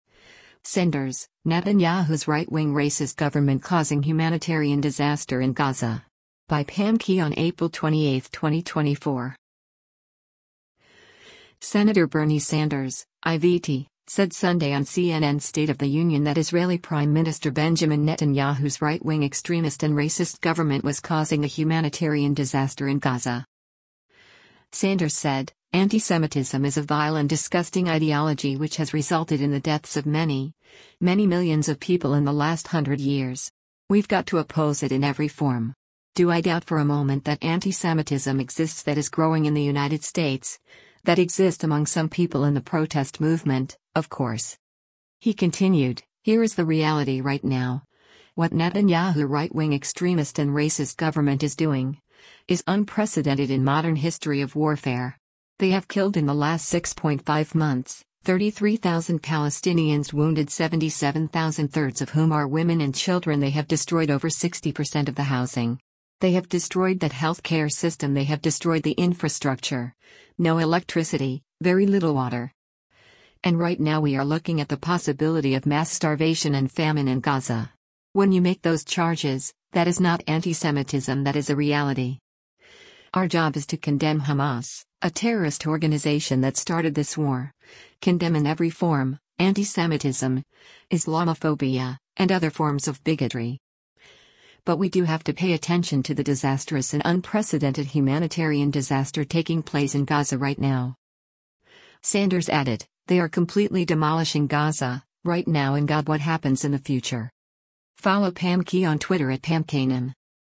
Senator Bernie Sanders (I-VT) said Sunday on CNN’s “State of the Union” that Israeli Prime Minister Benjamin Netanyahu’s “right-wing extremist and racist government ” was causing a humanitarian disaster in Gaza.